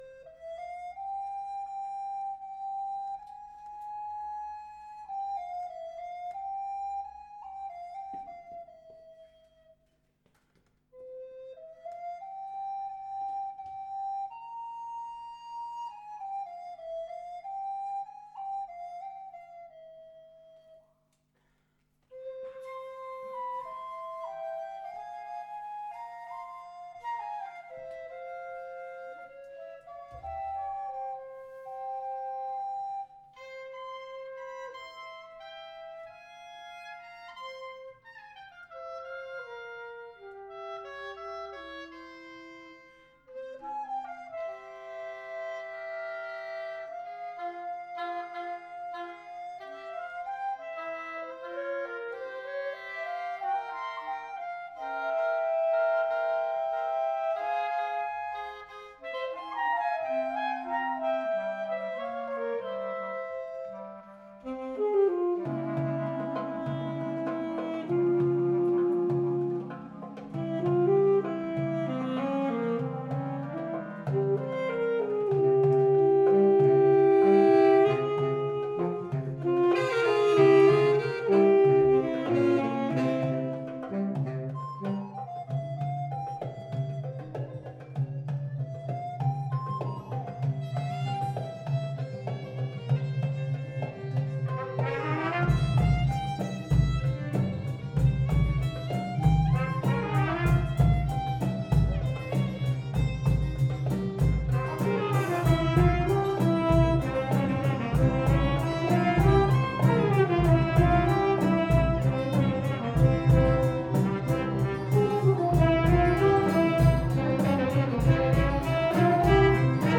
Musique Klezmer, 2019
Nous étions 20 musiciens pour  travailler « Bheymes Handel »
Le stage s’est terminé par un concert avec en première partie les stagiaires
saxophones soprano, ténor et basse
saxophone baryton
batterie, percussion
piano.
Traditionnel Klezmer